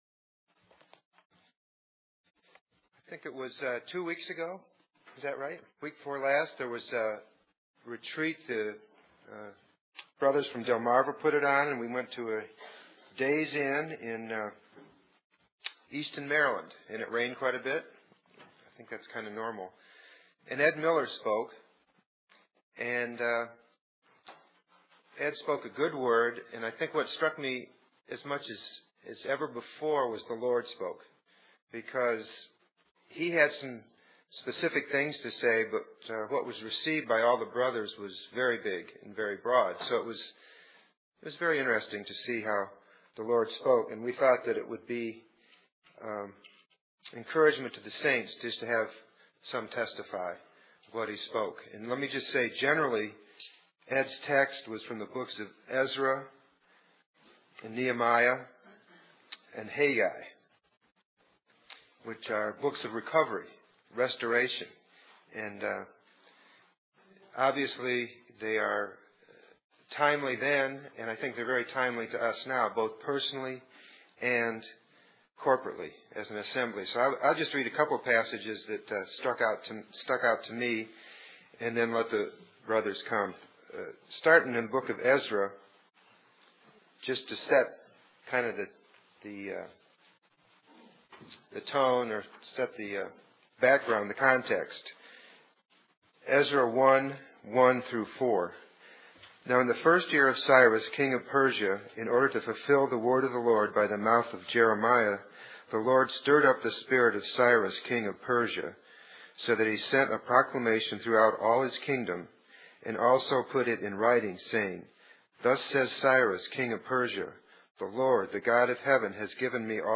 Testimonies From The 2005 Delmarva Men's Retreat